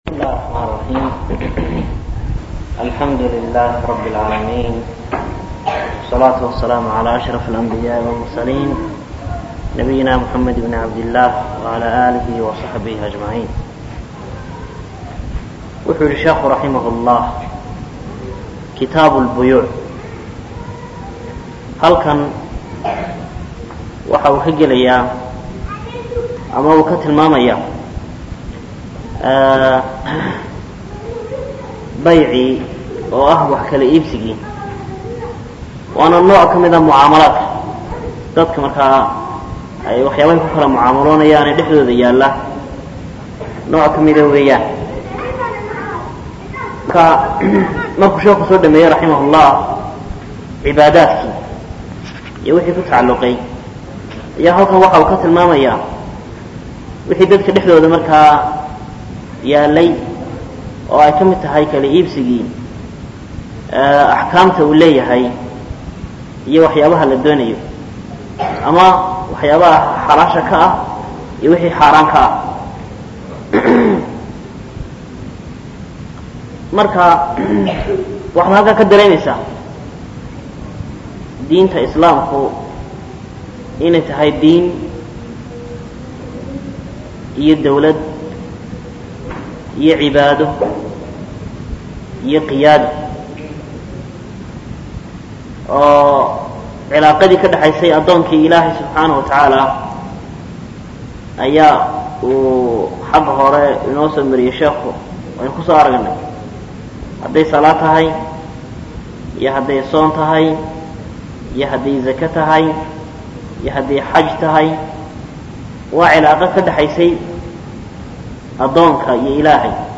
Darsiga 51aad